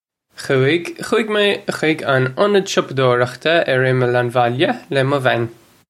Khoo-ig. Khoo-ig may khig un unnid shoppa-dore-ukhta urr immull un wolya luh muh van.
This is an approximate phonetic pronunciation of the phrase.